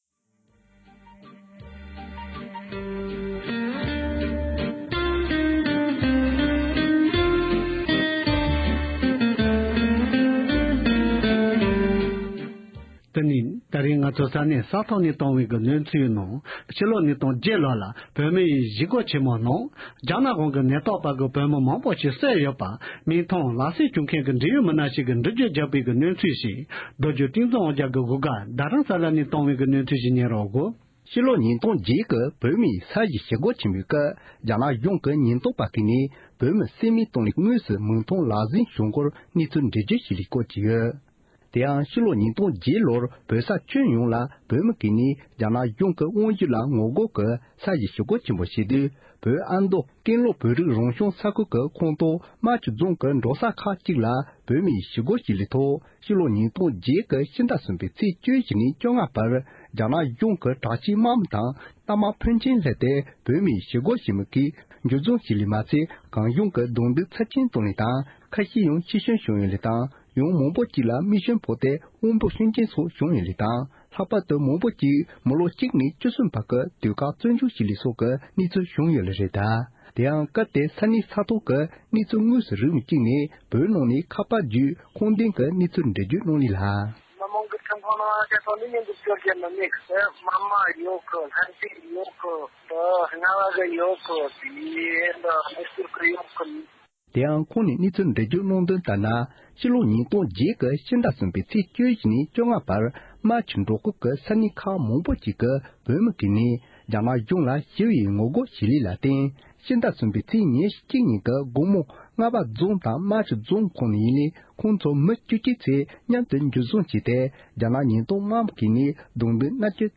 བོད་ནས་བོད་མི་ཞིག་གིས་༢༠༠༨ལོའི་ཞི་རྒོལ་སྐབས་རྒྱ་ནག་ཉེན་རྟོག་པས་བོད་མིའི་ཐོག་མེ་མདའ་བརྒྱབ་པའི་གནས་ཚུལ་ངོ་སྤྲོད་གནང་བ།
རྒྱ་ནག་ཉེན་རྟོག་པས་བོད་མིའི་ཐོག་མེ་མདའ་བརྒྱབ་ནས་བསད་པ་དངོས་སུ་མཐོང་མཁན་གྱི་བོད་མི་ཞིག་གིས།